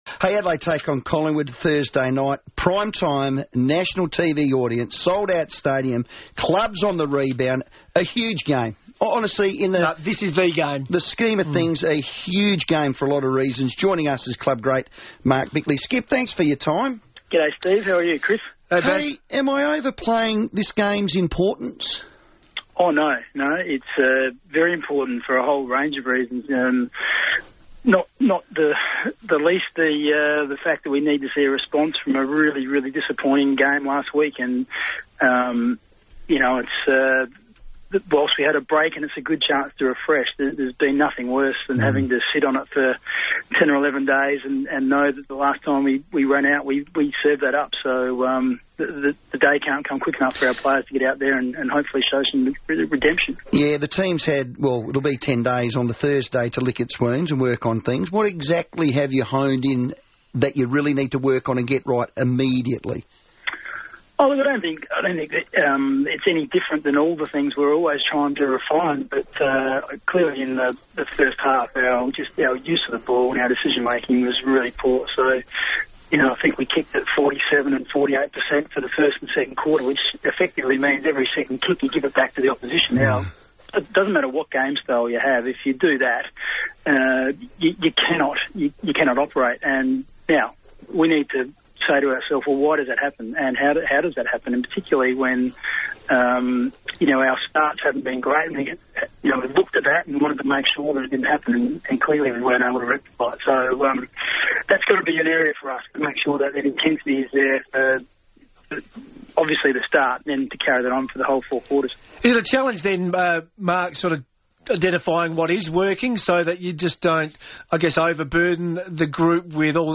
Mark Bickley joined the FIVEaa Sports Show ahead of Adelaide's Thursday night blockbuster against Collingwood.